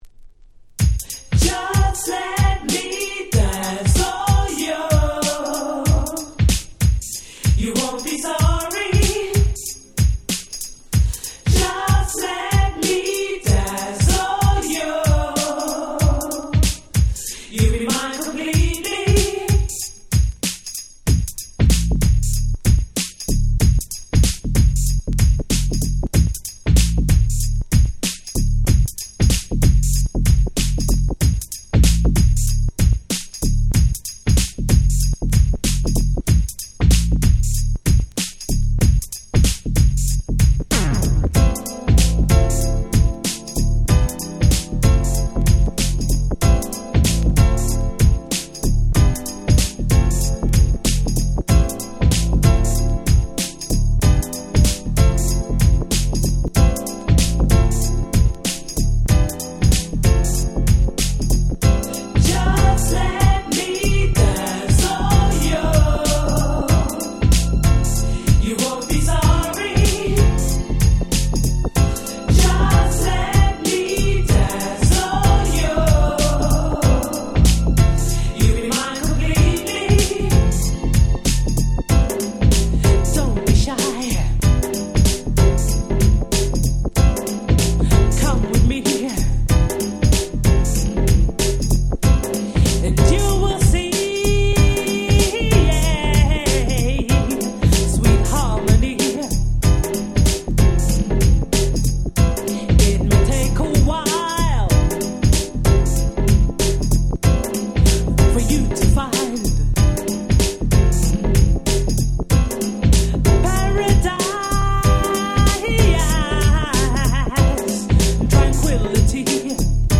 89' Super Nice UK Street Soul / R&B !!
ブラコン感覚で聴けちゃうめちゃ良い女性Vocal物です！